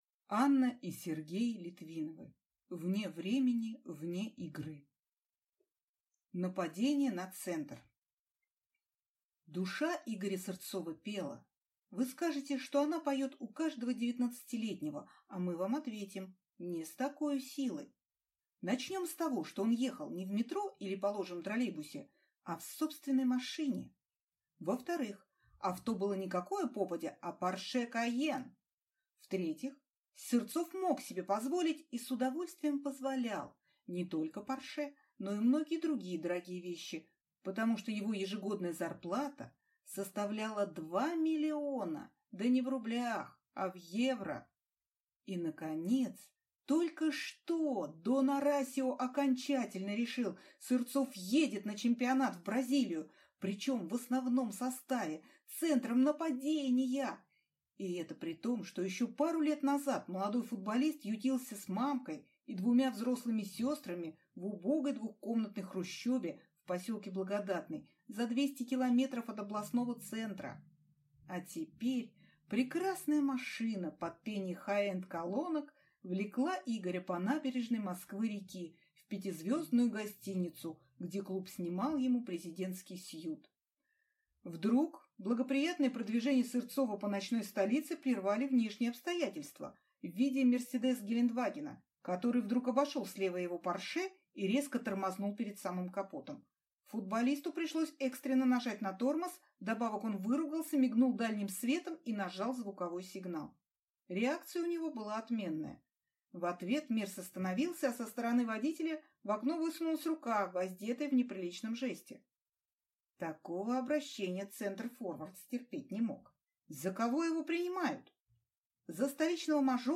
Аудиокнига Вне времени, вне игры | Библиотека аудиокниг
Прослушать и бесплатно скачать фрагмент аудиокниги